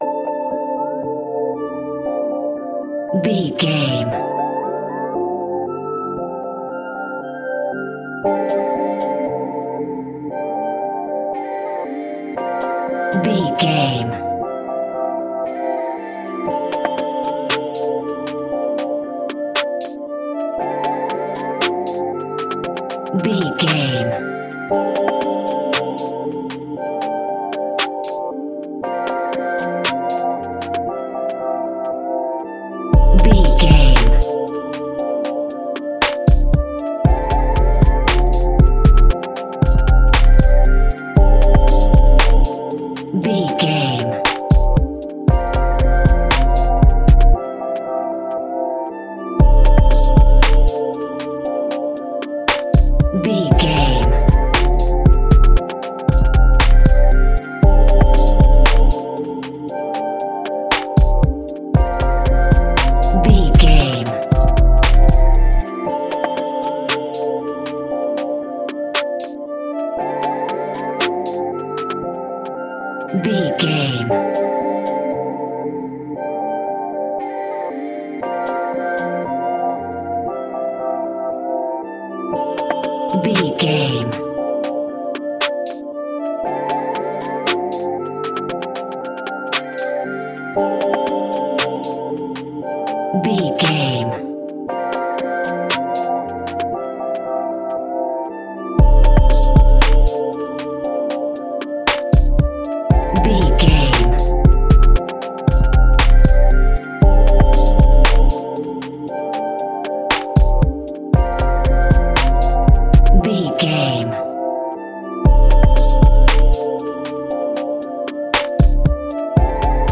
Aeolian/Minor
drums
dreamy
relaxed
mellow
uplifting
urban
synthesiser